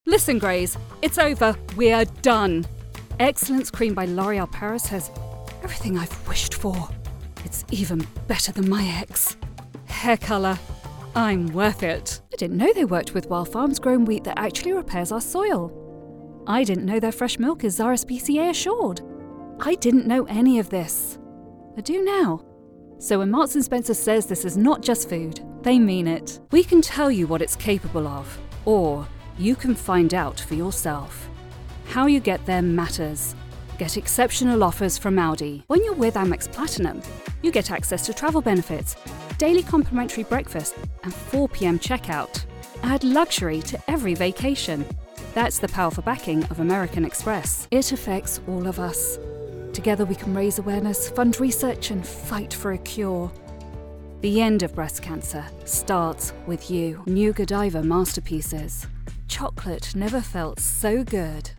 A silky, gentle, engaging, and sincere voice
My genuine English accent is fun, friendly, soothing, trustworthy, sophisticated ... or whatever you need it to be.
Commercial
• In-home isolation booth
commercial.mp3